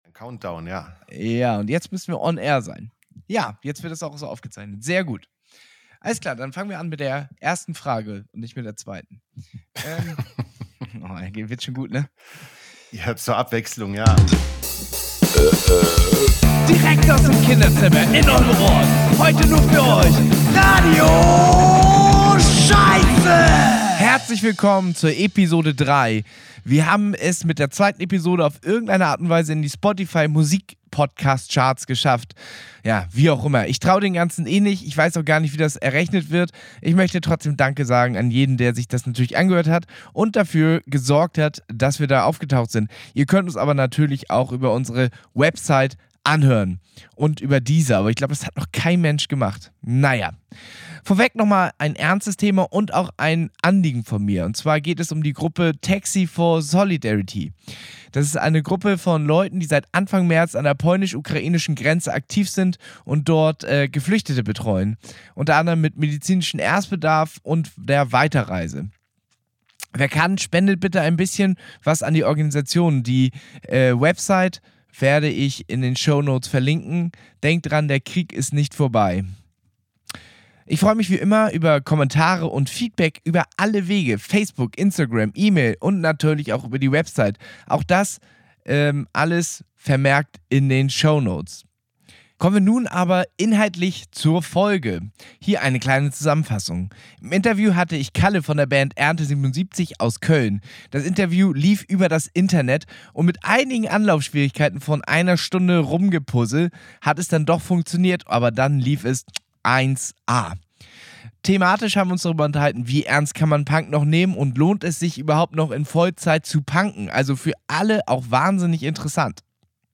Wir haben uns über ironischen Punk, Punken auf Vollzeit und Verschwörungsmythen, die den Punks von den Rechten geklaut wurde. Das erste Interview per Internet und es klappte nach einigen Komplikationen doch einwandfrei!